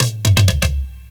DRUMFILL14-L.wav